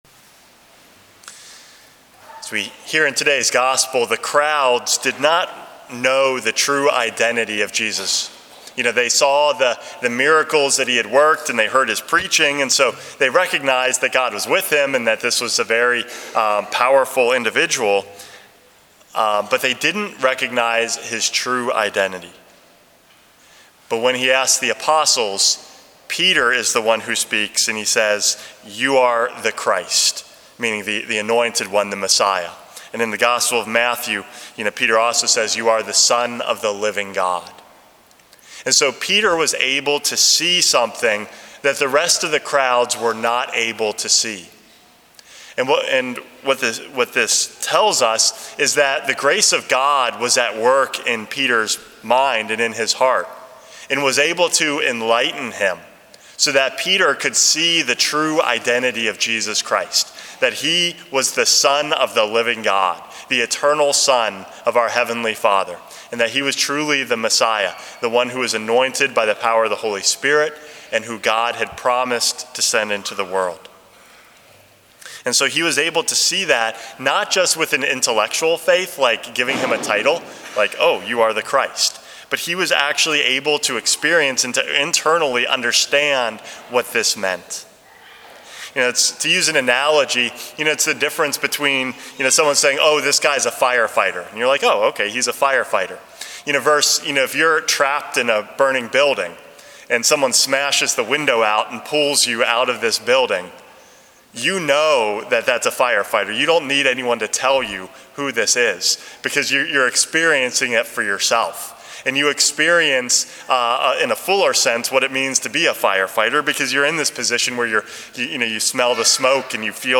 Homily #414 - Who Peter Saw